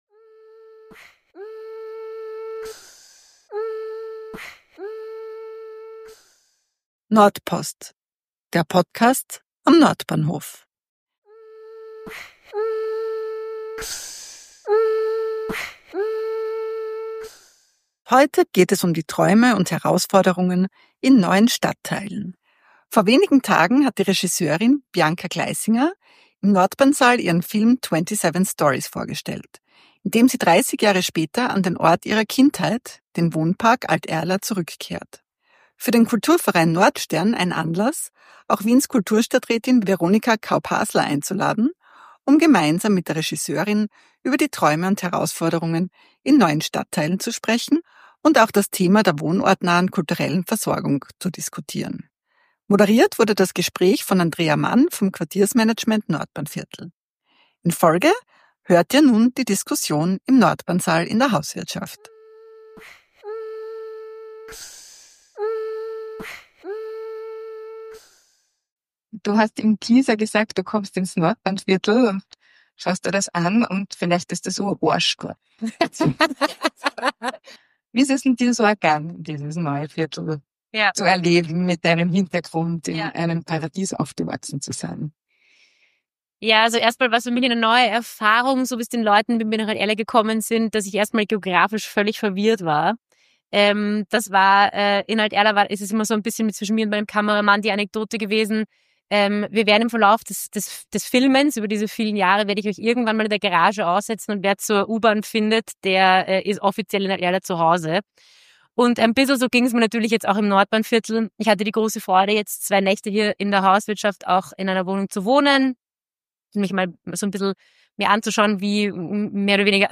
#55 - Podiumsdiskussion: Träume und Herausforderungen im Stadtentwicklungsgebiet